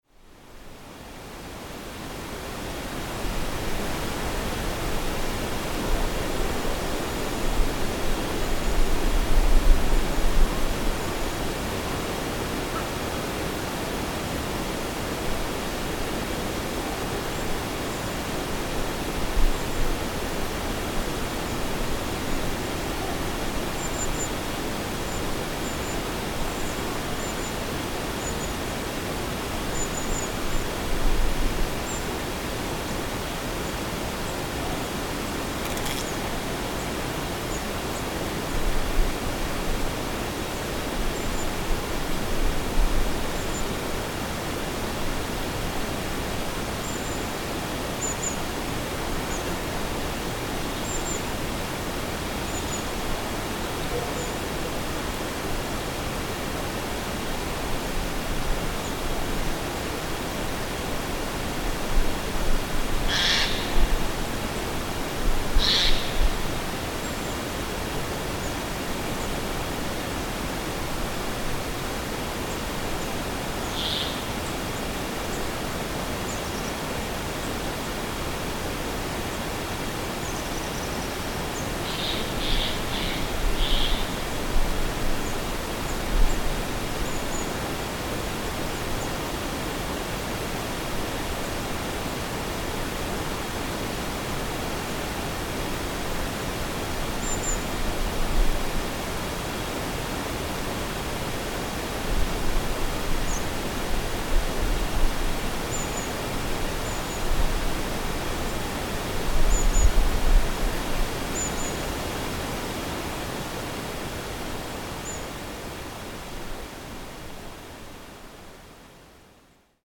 Nest of vultures in Unha